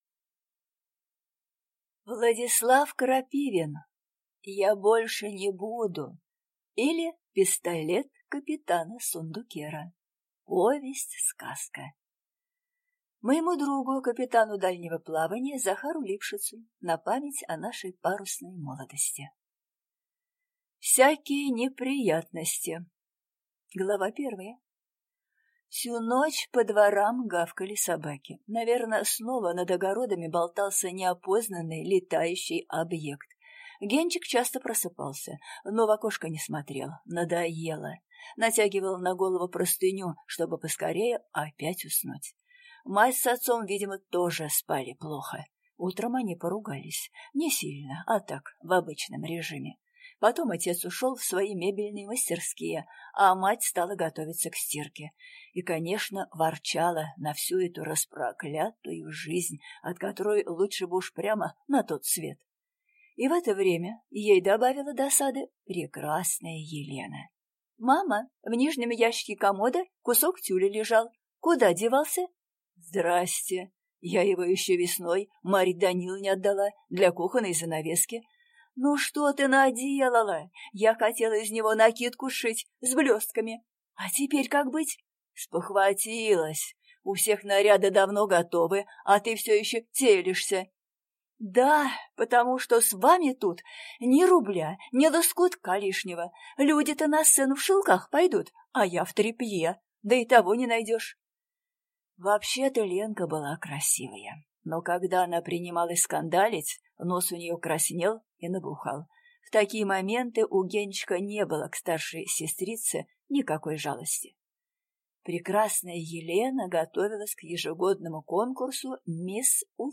Аудиокнига «Я больше не буду» или Пистолет капитана Сундуккера | Библиотека аудиокниг